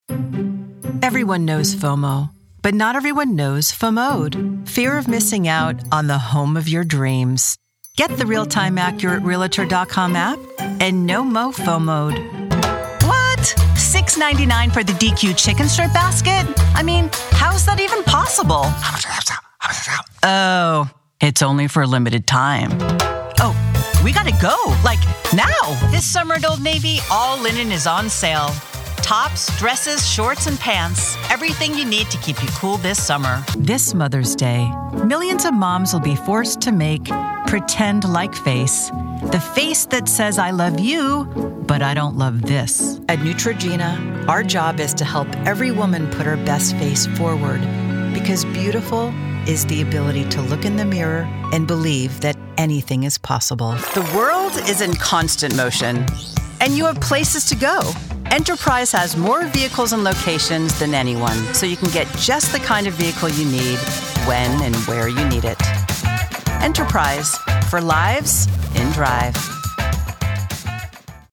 Demos
Valley Girl, Jewish Mother, Italian Mother
Young Adult
Middle Aged
Commercial